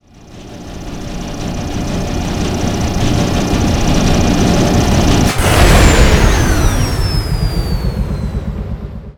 dronein.wav